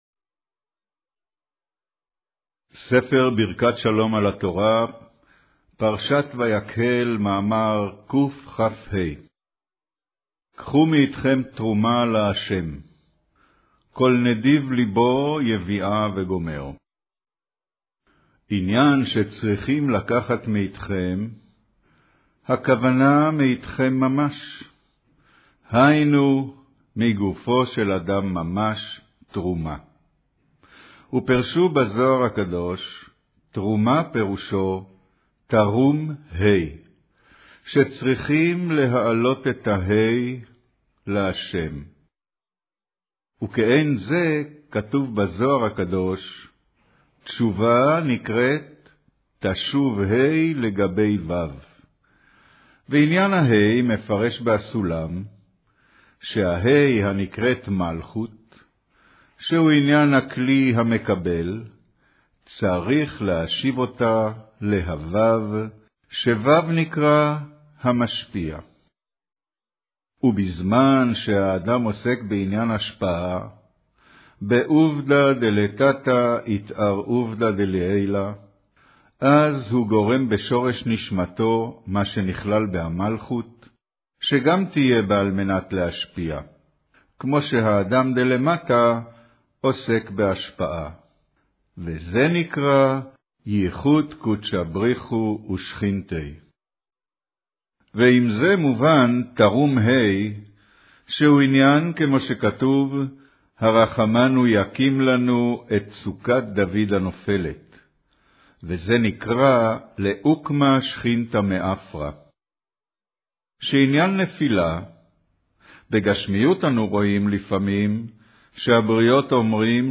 אודיו - קריינות פרשת ויקהל, מאמר קחו מאתכם תרומה לה'